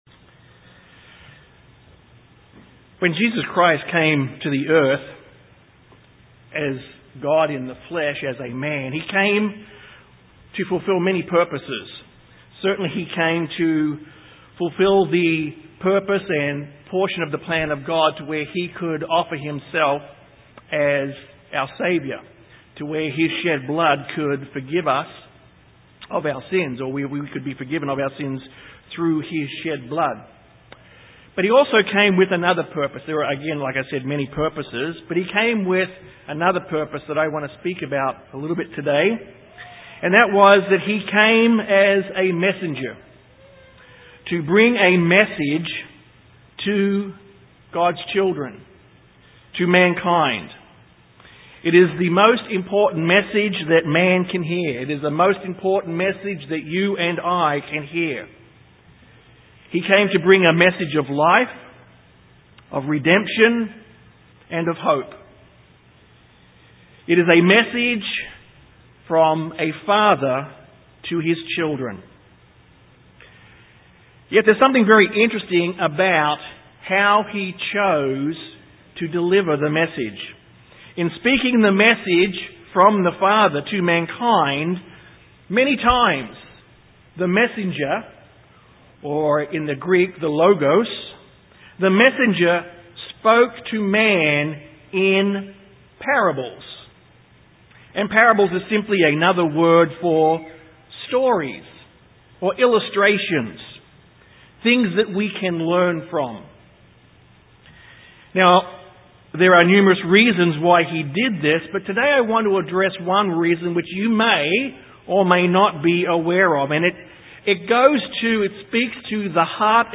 Why did He do that and what is there for us to learn from these parables? This sermon will show us in Christ's own words why He so often spoke in parables and explore some of the lessons for us to draw from two such parables in Matt 13.